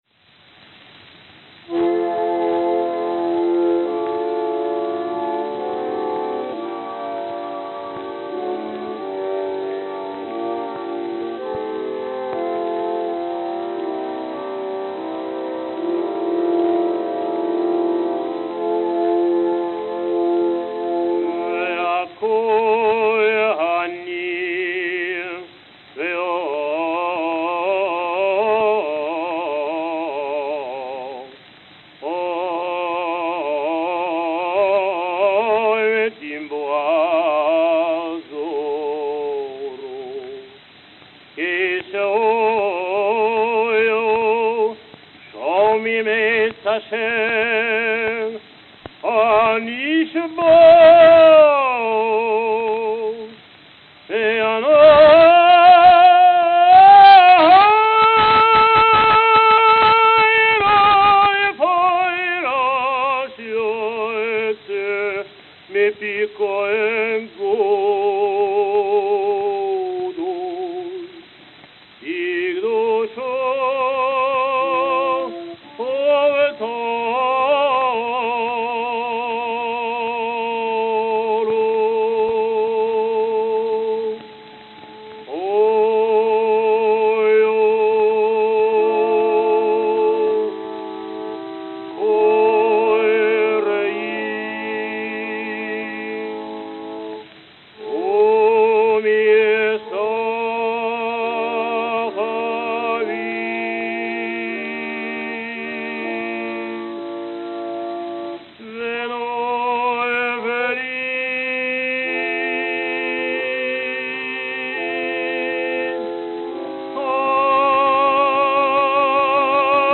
Obercantor Sawel Kwartin Obercantor Sawel Kwartin
Vienna, Austria Vienna, Austria
Note: Stressed loud passages.